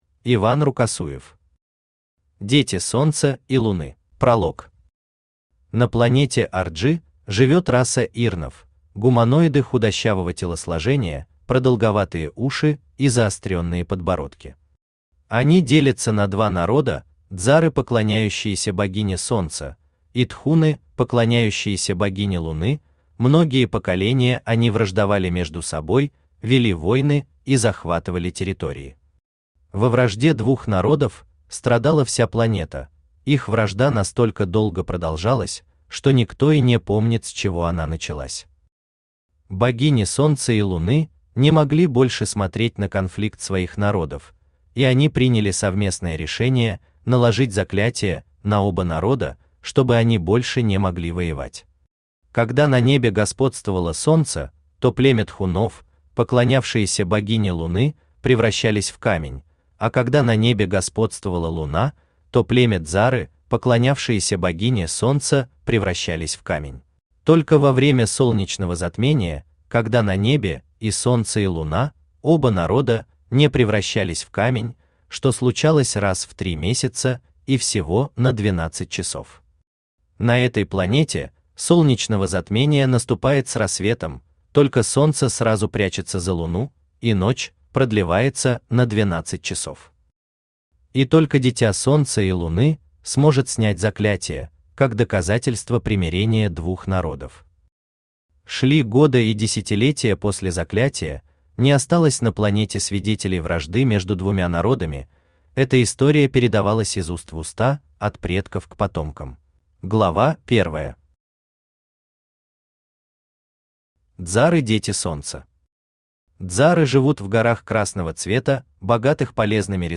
Аудиокнига Дети Солнца и Луны | Библиотека аудиокниг
Aудиокнига Дети Солнца и Луны Автор Иван Рукосуев Читает аудиокнигу Авточтец ЛитРес.